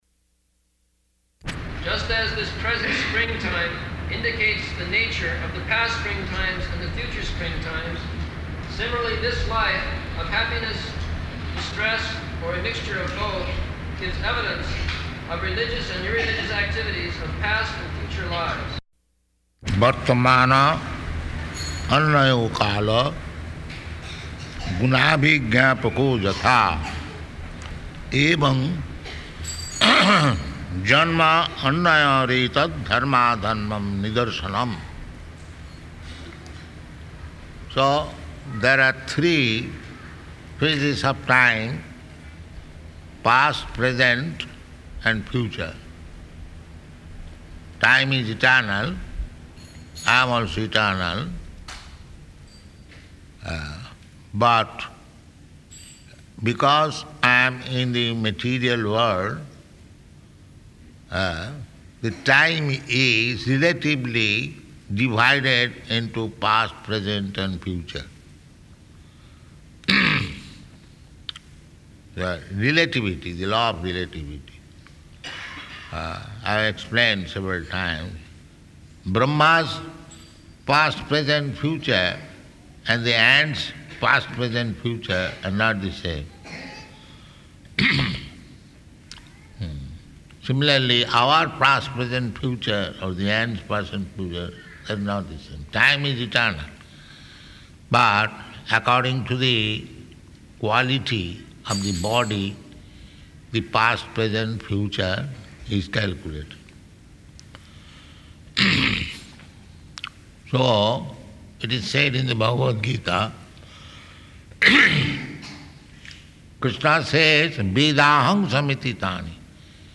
Location: Dallas